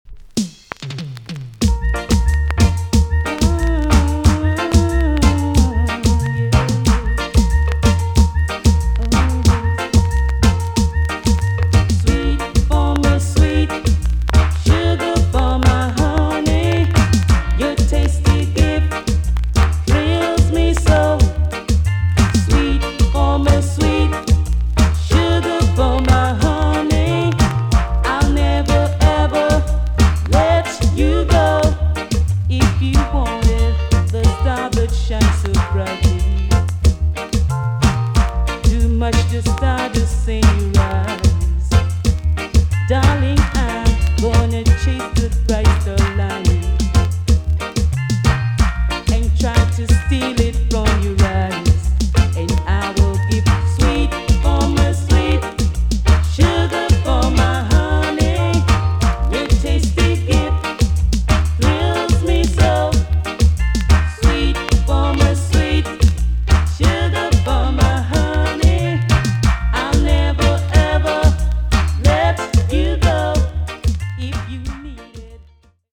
TOP >80'S 90'S DANCEHALL
EX-~VG+ 少し軽いチリノイズがありますがキレイです。